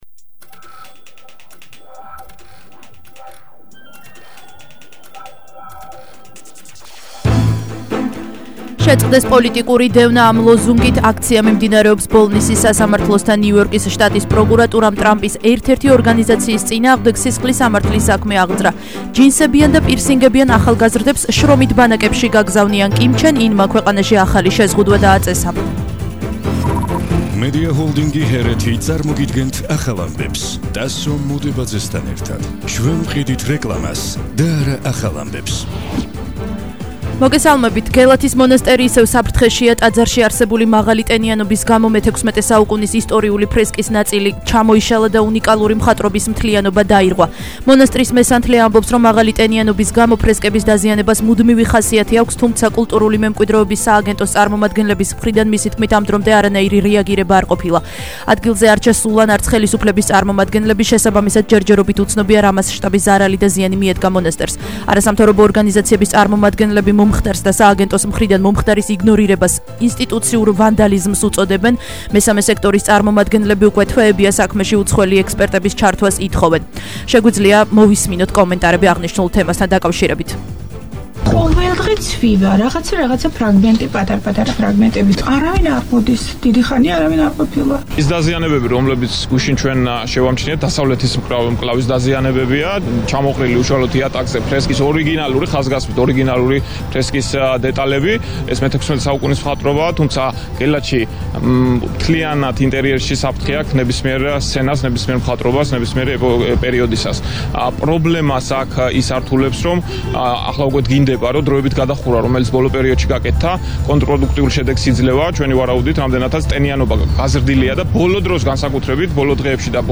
ახალი ამბები 16:00 საათზე –19/05/21 – HeretiFM